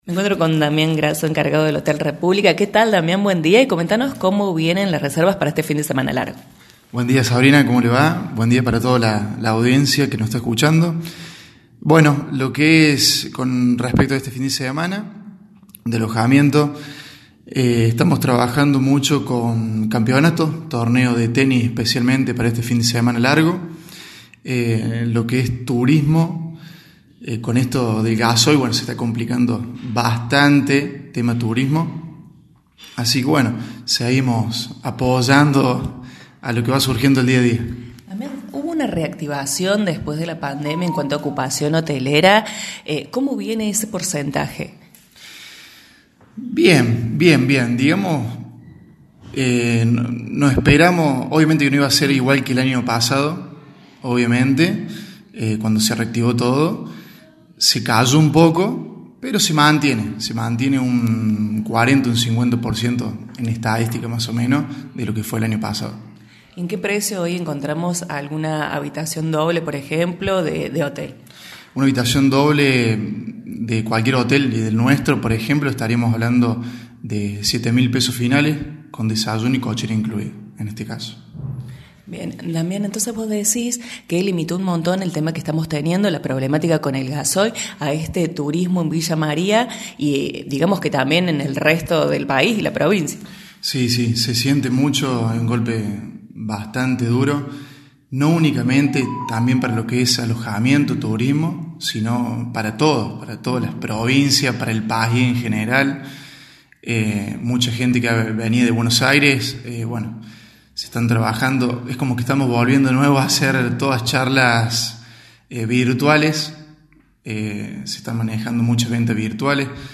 En La Mañana Informal dialogamos con